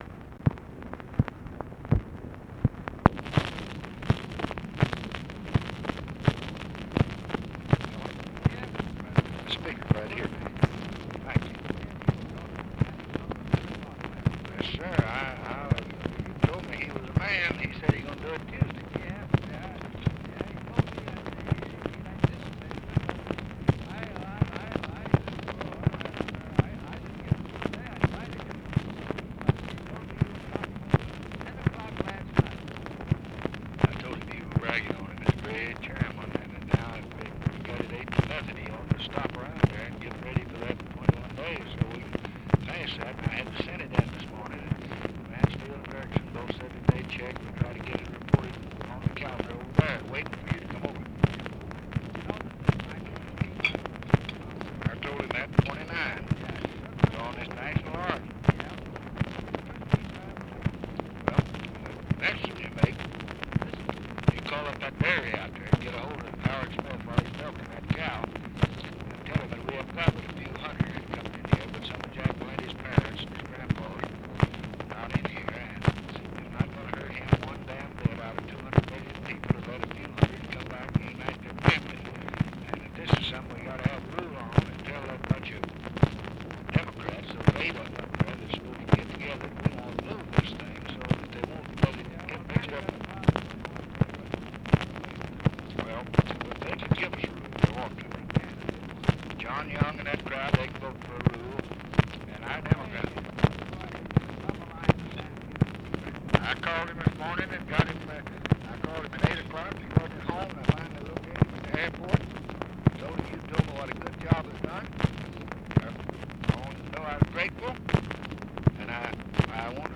ALMOST INAUDIBLE DISCUSSION OF VOTE LAST NIGHT ON POVERTY BILL?, COMMITTEE VOTE ON IMMIGRATION BILL?; LBJ'S CALL TO SAM GIBBONS?; LBJ INVITES MCCORMACK TO LBJ RANCH; GEORGE FELDMAN'S APPOINTMENT AS AMBASSADOR TO MALTA; HIGHER EDUCATION, TAFT-HARTLEY BILLS
Conversation with JOHN MCCORMACK and FRANK THOMPSON, July 23, 1965